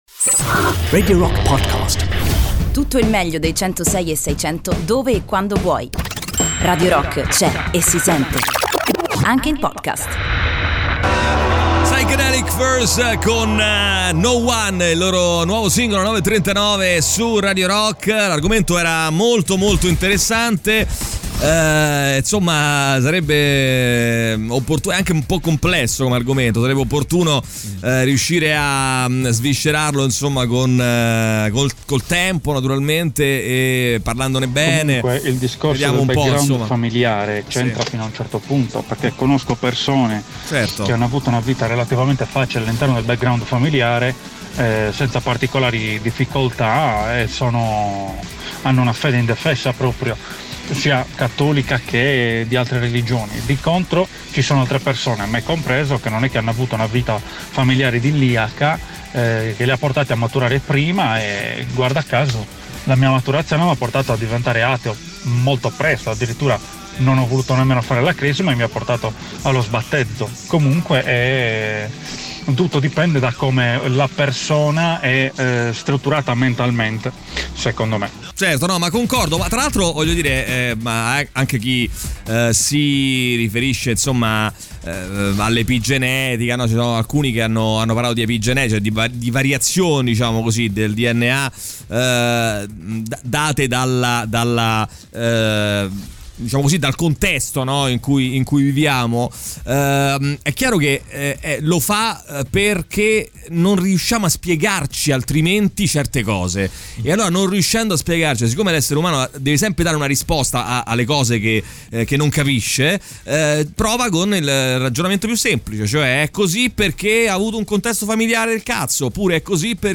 Intervista: "Valerio Lundini" (11-05-20)
in collegamento telefonico con Valerio Lundini durante il THE ROCK SHOW.